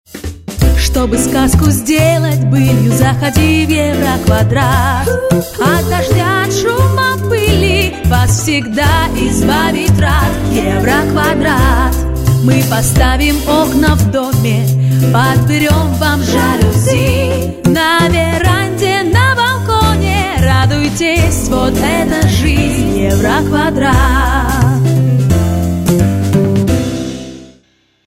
Радио-ролик Категория: Аудио/видео монтаж